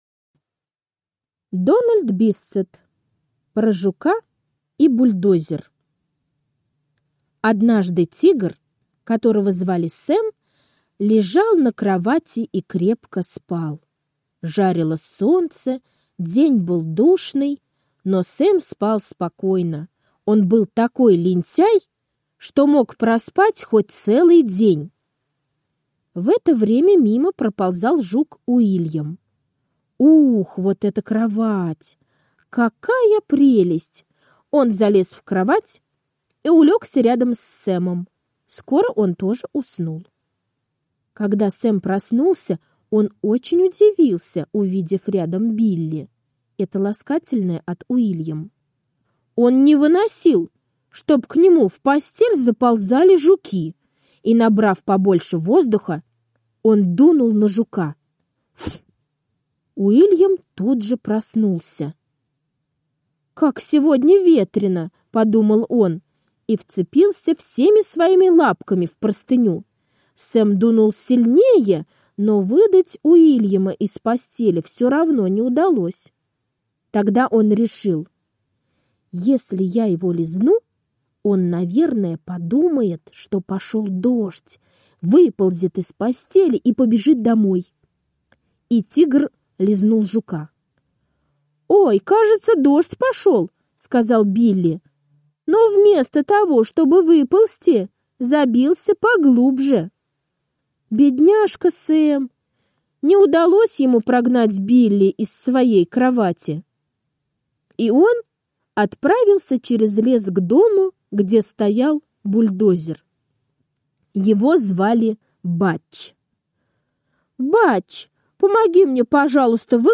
Аудиосказка «Про жука и бульдозер»